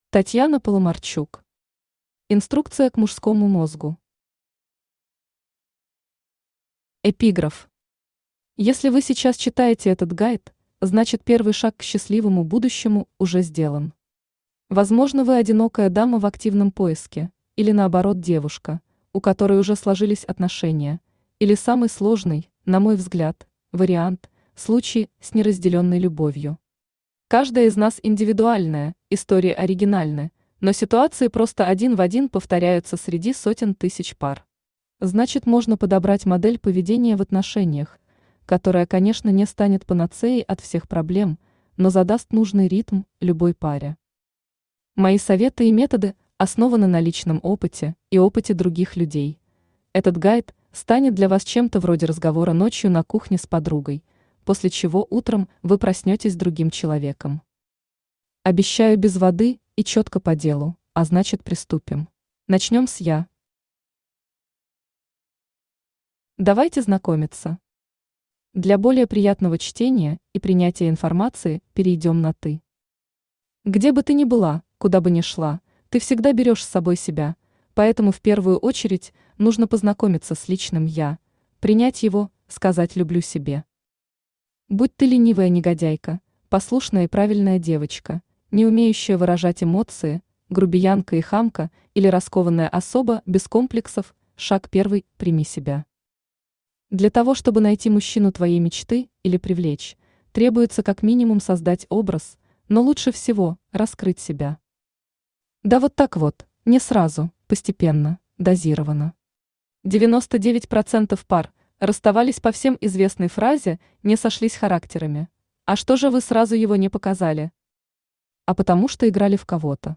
Aудиокнига Инструкция к мужскому мозгу Автор Татьяна Сергеевна Паламарчук Читает аудиокнигу Авточтец ЛитРес.